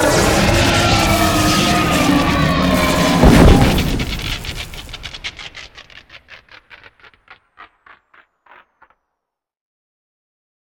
bigdie1.ogg